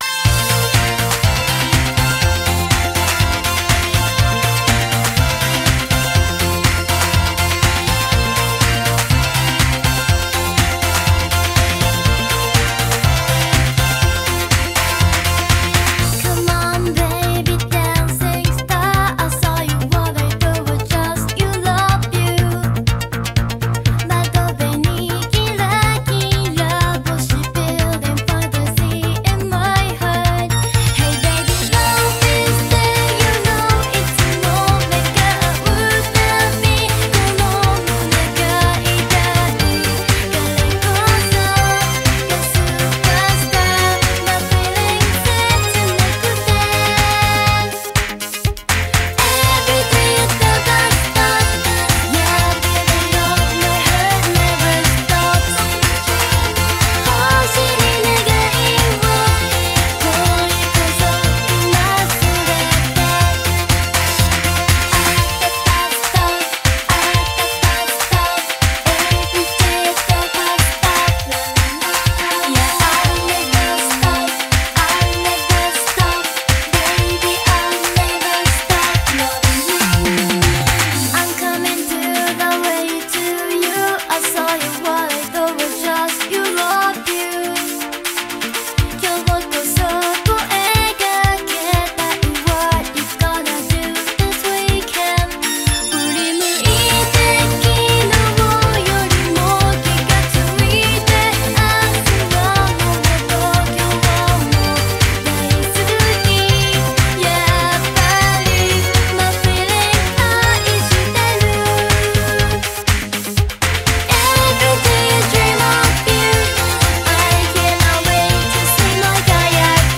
Audio QualityPerfect (High Quality)
Japanese pop superstar